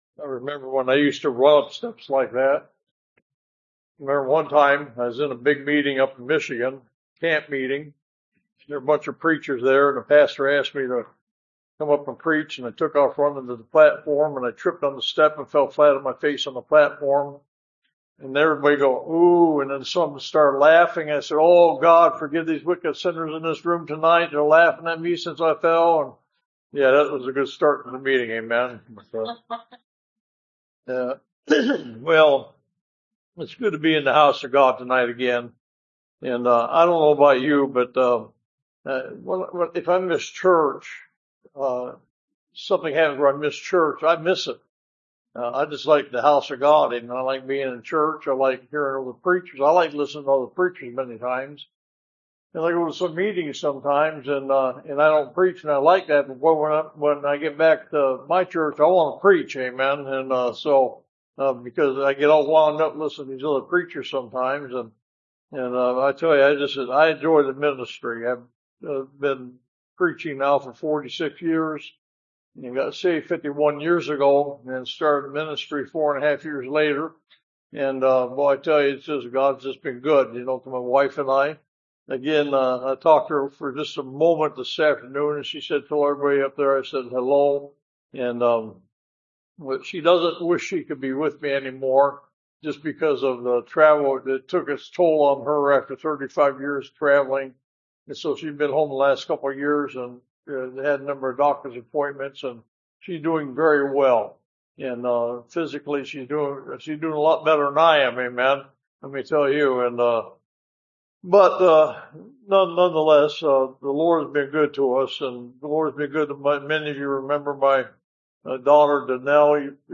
Revival Preachings Service Type: Sunday Evening